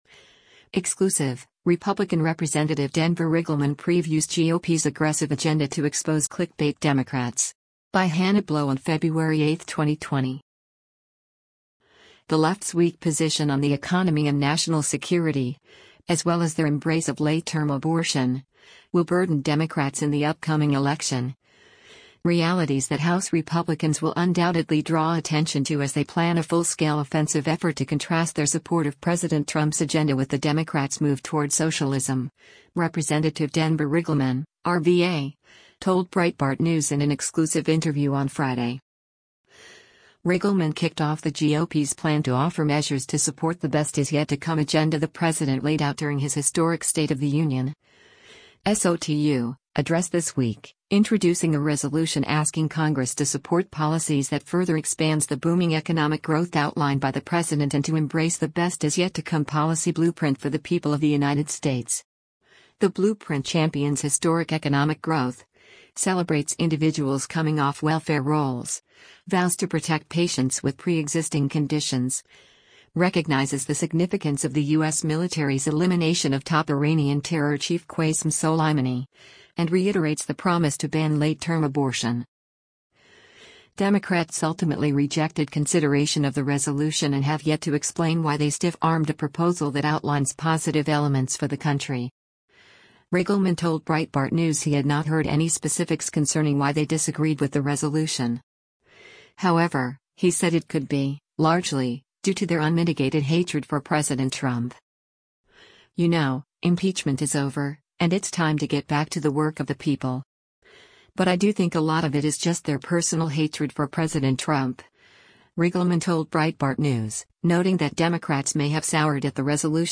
The left’s weak position on the economy and national security, as well as their embrace of late-term abortion, will burden Democrats in the upcoming election — realities that House Republicans will undoubtedly draw attention to as they plan a full-scale offensive effort to contrast their support of President Trump’s agenda with the Democrats’ move toward socialism, Rep. Denver Riggleman (R-VA) told Breitbart News in an exclusive interview on Friday.